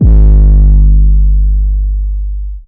PRIVATE 808S (105).wav